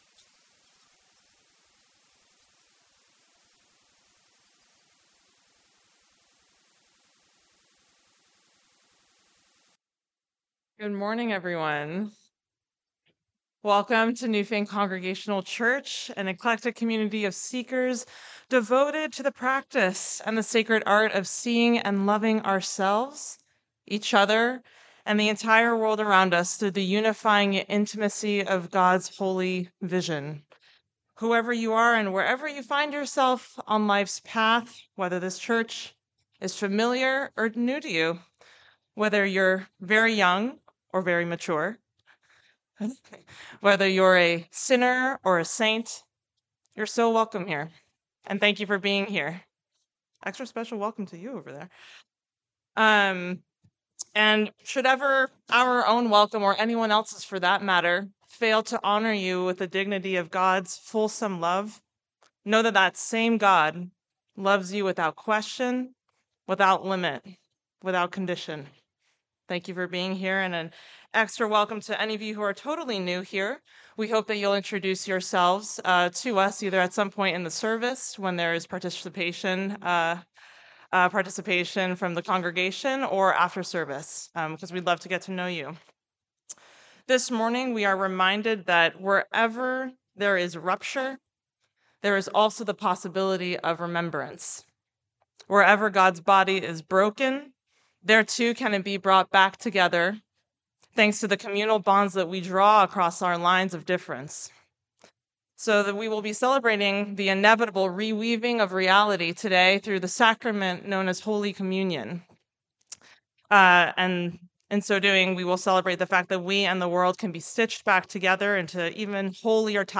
August 3, 2025 Service Digital Bulletin 08.03.25 – Pentecost8